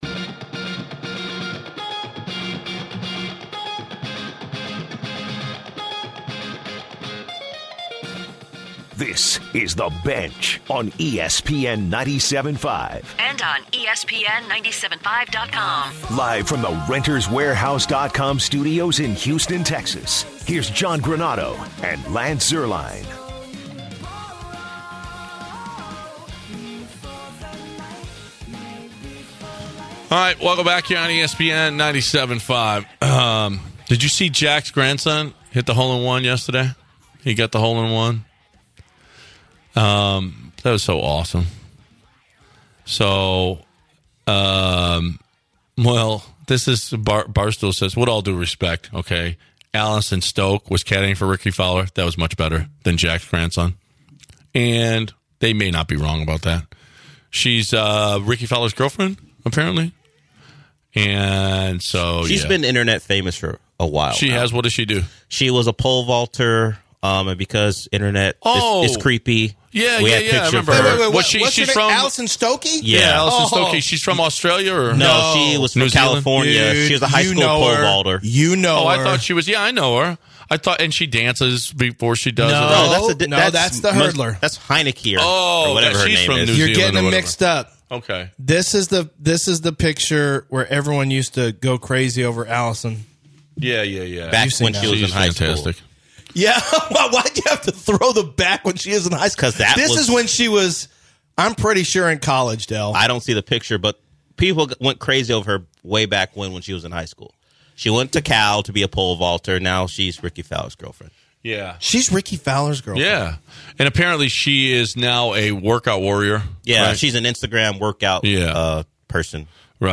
They then get a few history lessons from listeners and comment on racism.